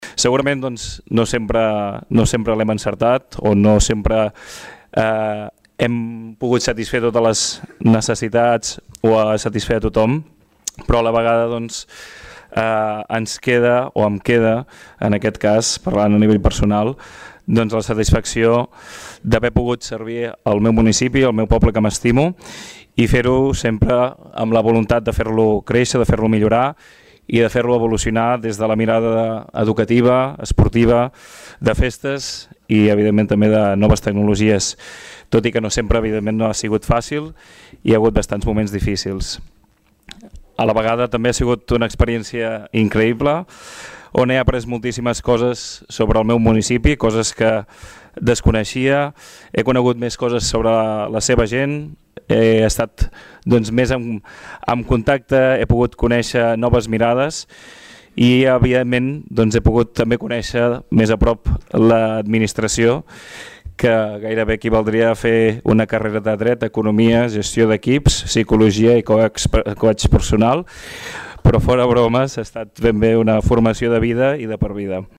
Tots els regidors que plegaven també van realitzar un darrer missatge d’acomiadament.